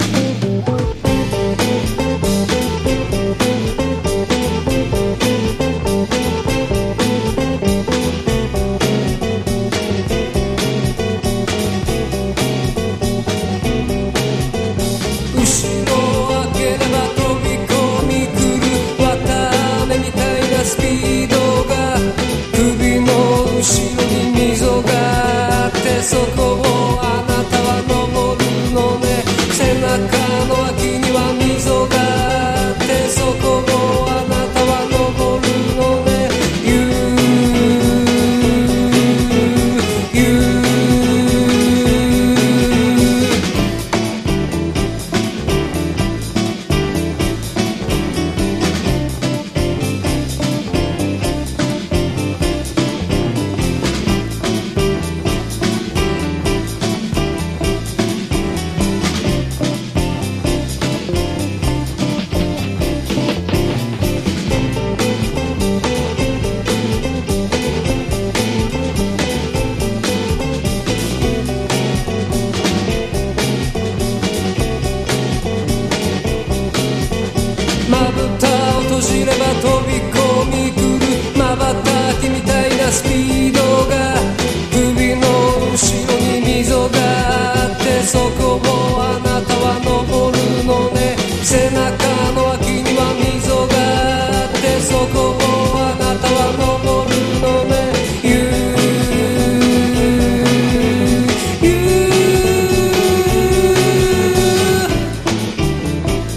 NO WAVE / POST PUNK、東京ロッカーズ、あぶらだこ、ゆらゆら帝国好きまで必聴！